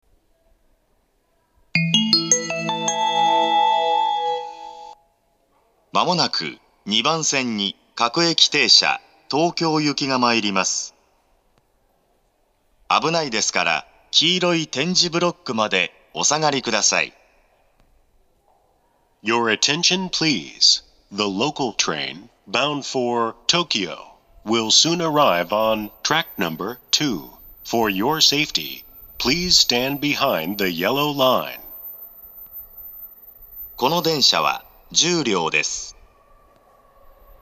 ２番線接近放送
hatchobori2bansen-sekkin3.mp3